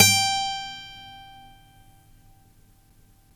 Index of /90_sSampleCDs/Roland L-CDX-01/GTR_Steel String/GTR_ 6 String
GTR 6STR G09.wav